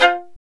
Index of /90_sSampleCDs/Roland - String Master Series/STR_Violin 4 nv/STR_Vln4 % marc